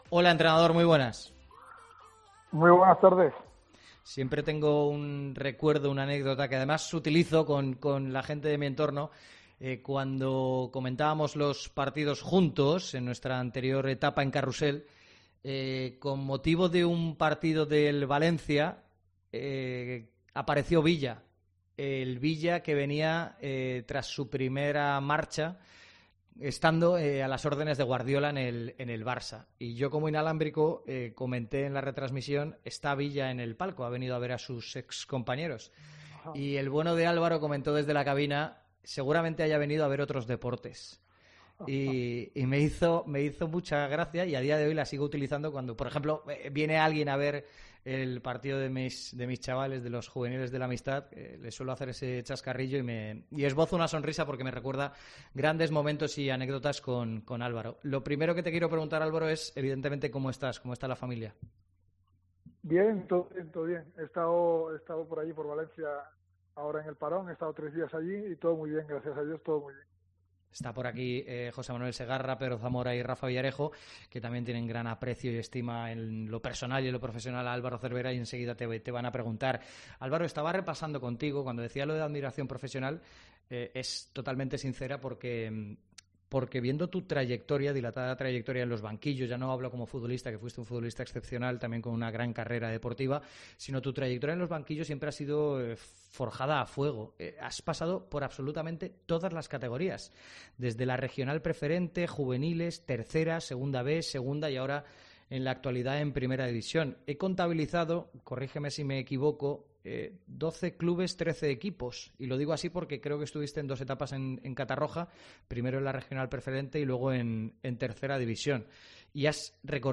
AUDIO. Entrevista a Álvaro Cervera en Deportes COPE Valencia